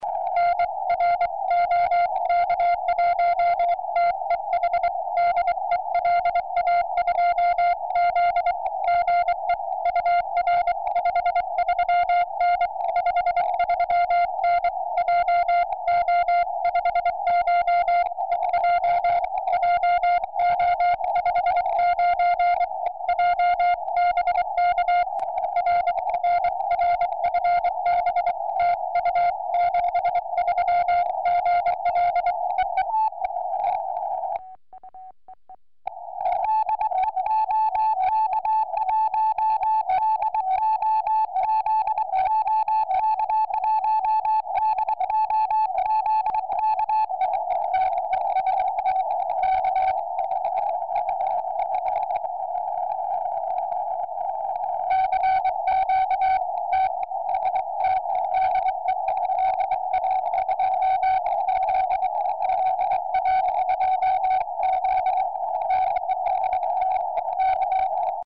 70cm CW spojení - mp3 se známým klubem LA2Z z JO59EJ = 1062km.